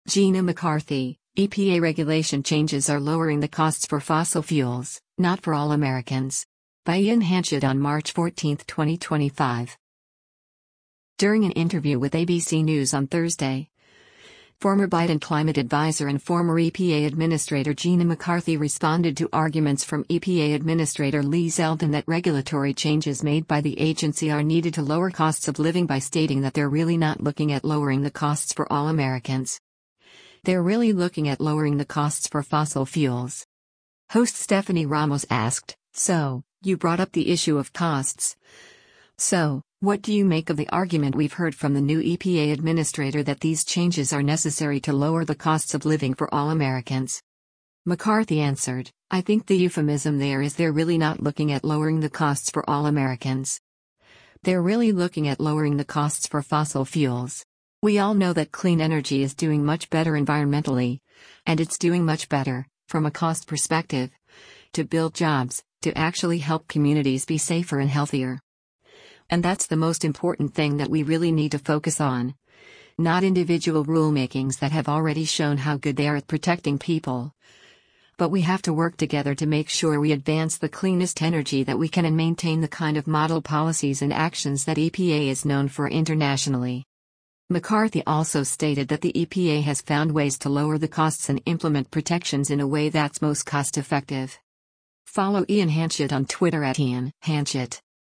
During an interview with ABC News on Thursday, former Biden Climate Adviser and former EPA Administrator Gina McCarthy responded to arguments from EPA Administrator Lee Zeldin that regulatory changes made by the agency are needed to lower costs of living by stating that “they’re really not looking at lowering the costs for all Americans. They’re really looking at lowering the costs for fossil fuels.”